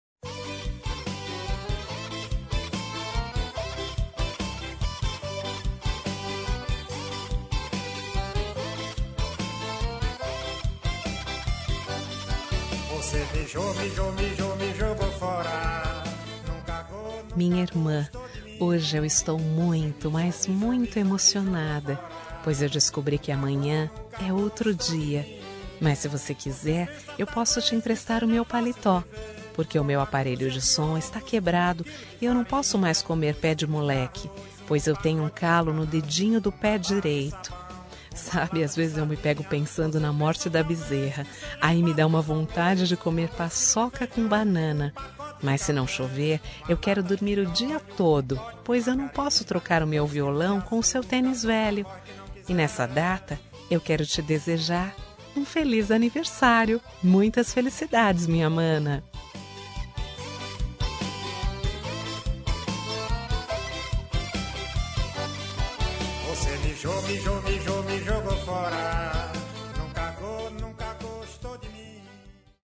Aniversário de Humor – Voz Feminina – Cód: 200109